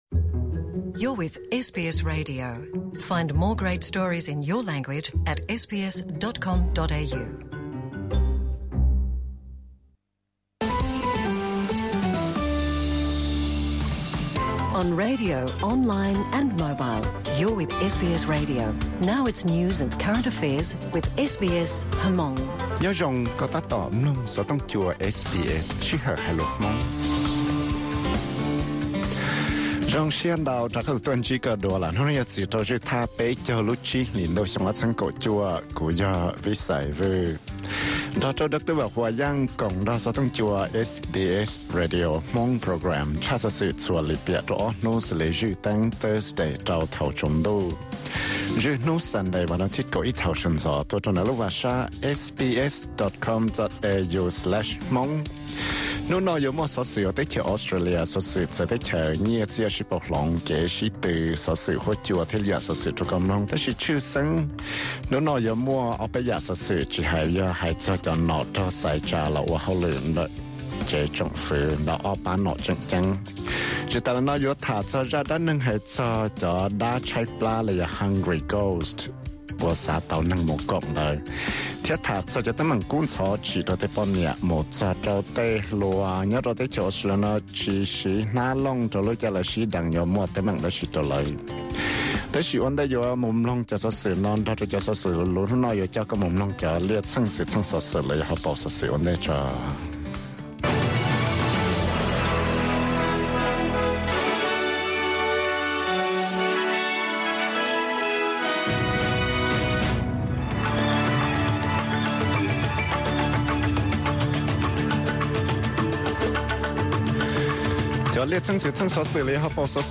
Listen to Thursday news from SBS Radio Hmong Program.